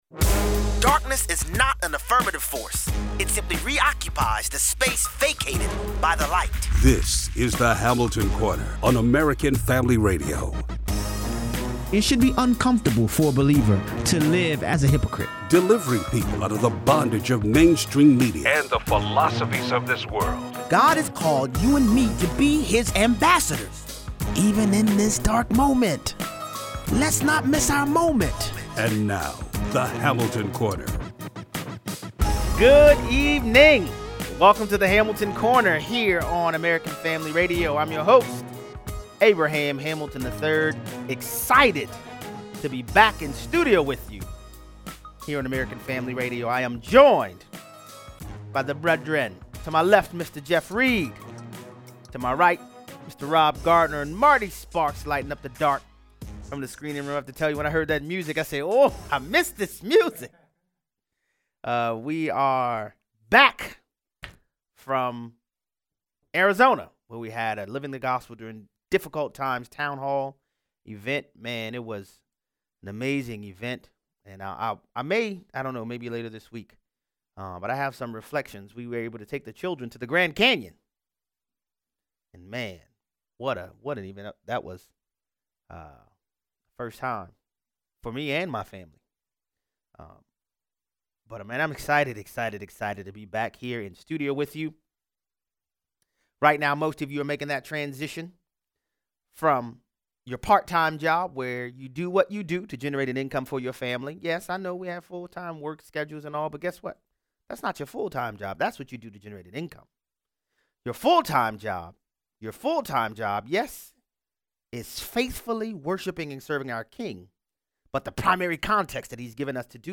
MSNBC hosts gleefully discuss the possibility of the Coronavirus being the thing to “take down a president.” Callers weigh in.